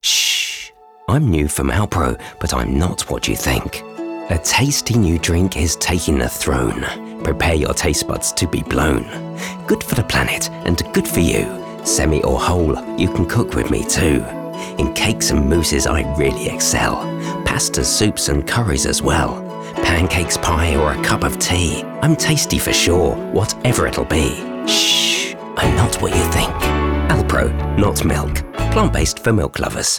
Englisch (Britisch)
Cool, Freundlich, Warm, Kommerziell, Corporate
Kommerziell